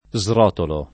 srotolo [ @ r 0 tolo ]